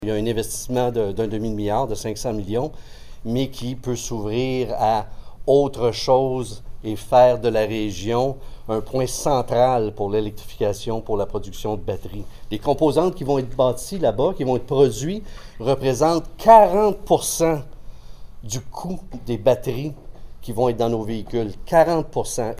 Au moins une cinquantaine de personnes, dont des élus municipaux de Bécancour et Nicolet, les députés provincial Donald Martel et fédéral Louis Plamondon, de même que des intervenants économiques de la Rive-Sud se sont réunis chez Auger Automobile de Nicolet, mercredi soir, pour célébrer l’annonce de la venue d’une usine de General Motors à Bécancour.